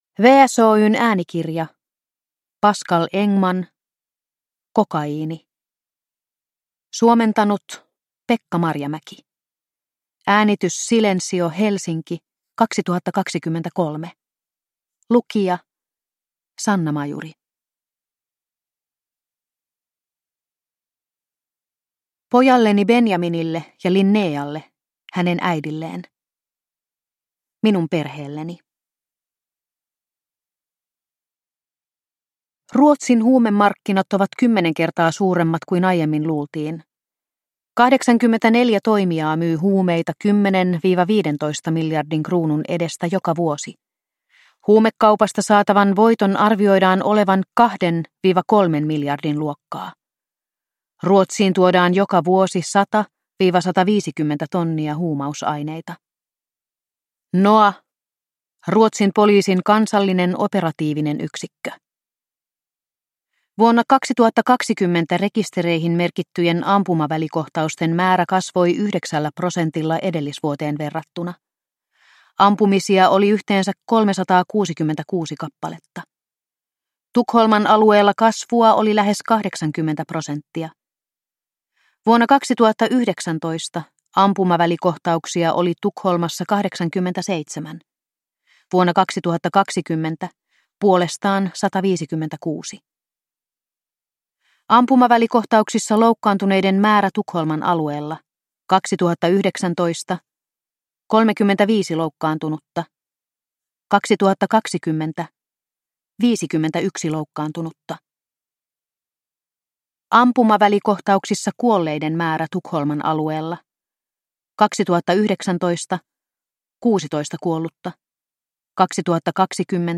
Kokaiini – Ljudbok – Laddas ner